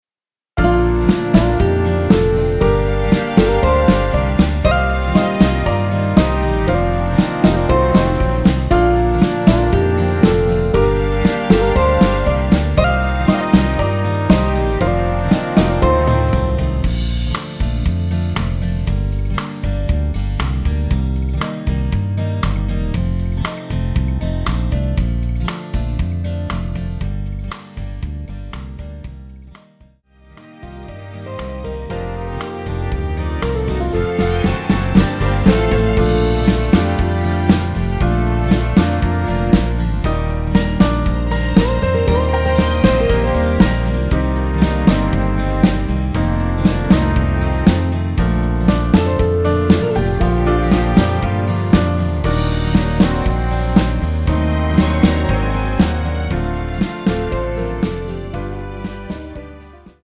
Bb
노래방에서 음정올림 내림 누른 숫자와 같습니다.
앞부분30초, 뒷부분30초씩 편집해서 올려 드리고 있습니다.
중간에 음이 끈어지고 다시 나오는 이유는